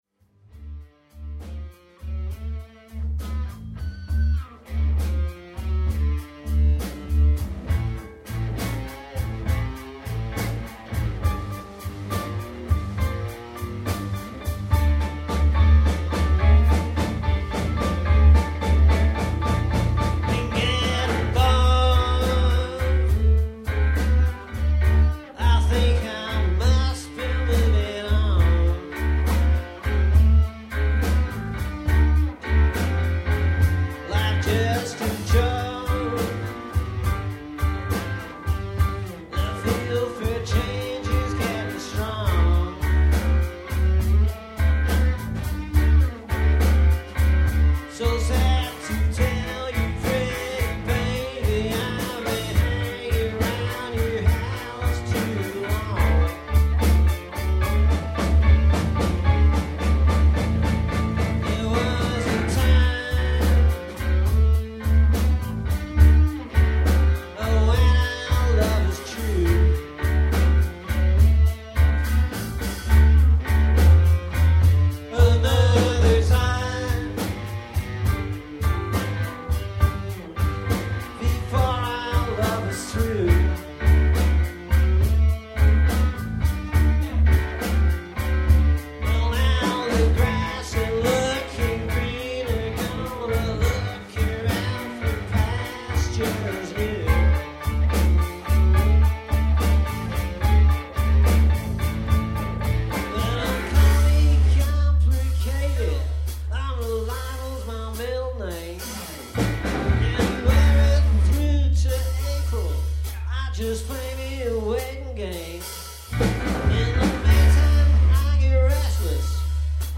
Guitar, harmonica, vocals
Bass, guitar, keyboards, vocals
Drums, vocals